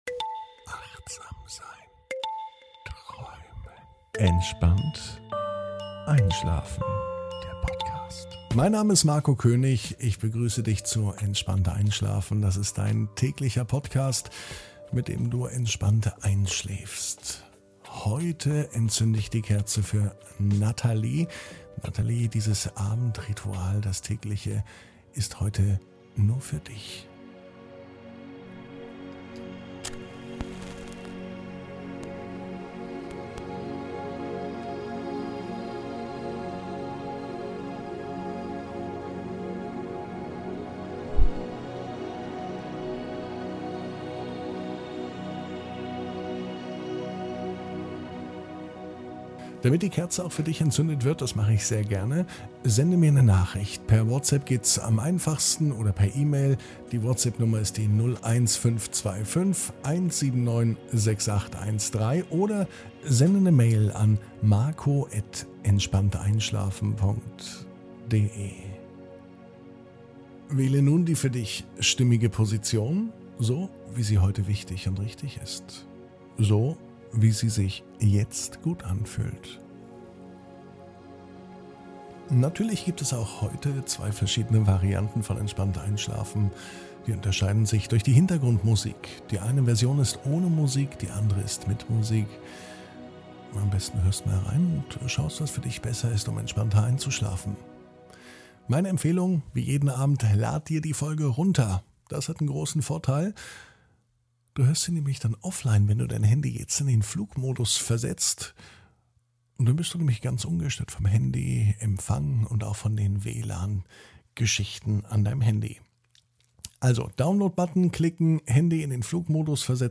(ohne Musik) Entspannt einschlafen am Sonntag, 14.08.22 ~ Entspannt einschlafen - Meditation & Achtsamkeit für die Nacht Podcast